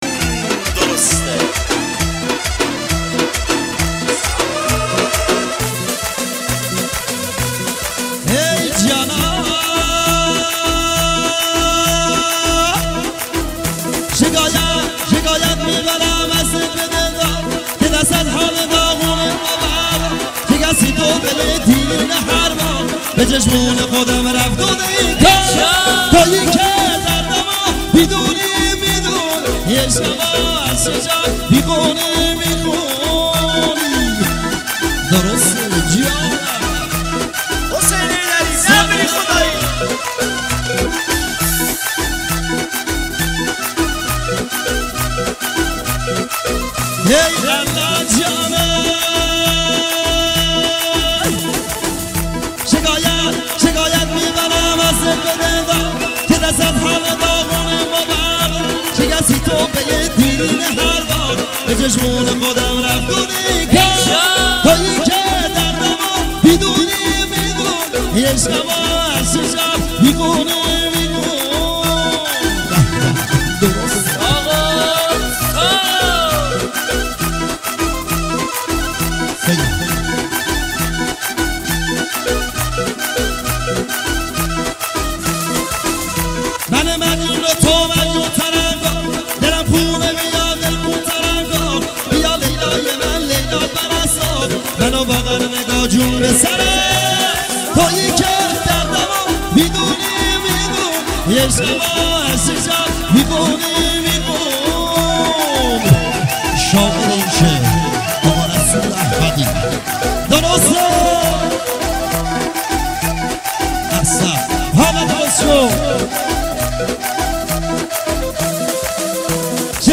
یک موسیقی شاد خراسانی است
یک آهنگ شاد ارکستر محلی هست
متن آهنگ شاد محلی :